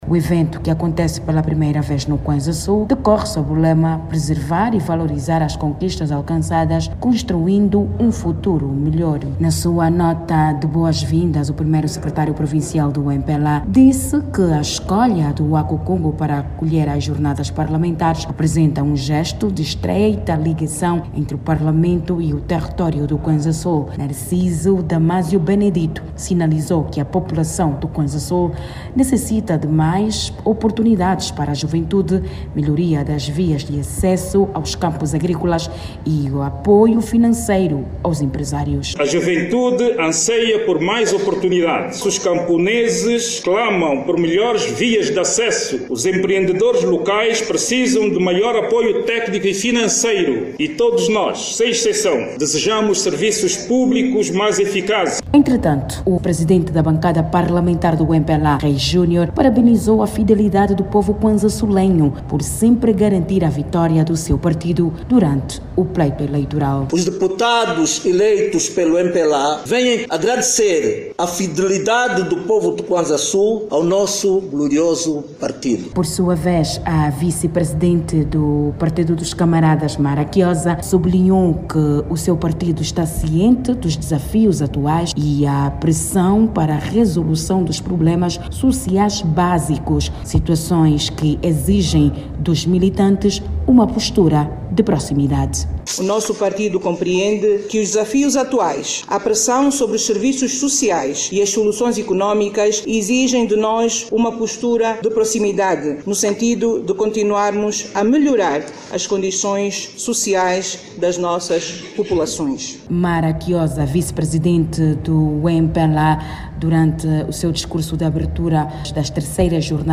A dirigente falava, nesta segunda-feira, durante a abertura das terceiras Jornadas Parlamentares do MPLA, que decorrem no município do Waku-Kungo, província do Cuanza-Sul.